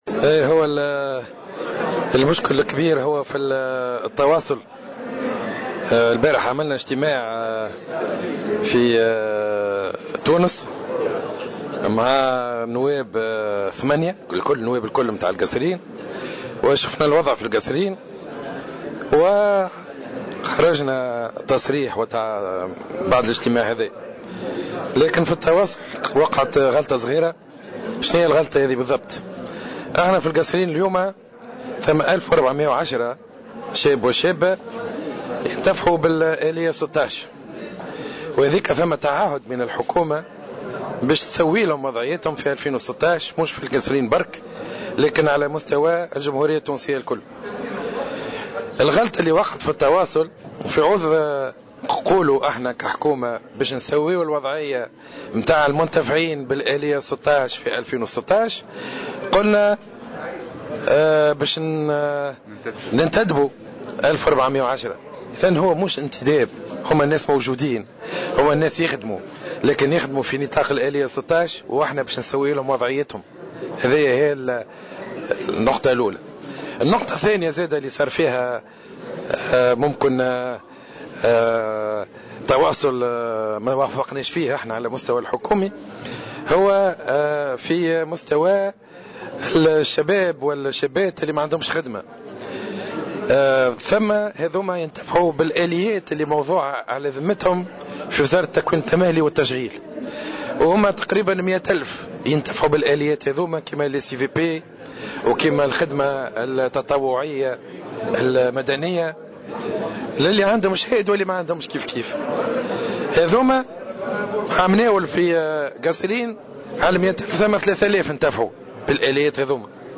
وأضاف الوزير في تصريح لمراسل "الجوهرة أف أم" بصفاقس أنه لا وجود لأي انتدابات جديدة، مشيرا إلى أن الأمر يتعلق بتسوية 1410 من المنتفعين بالآلية 16 خلال سنة 2016.